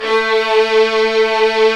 Index of /90_sSampleCDs/Roland LCDP13 String Sections/STR_Violins V/STR_Vls8 fff slo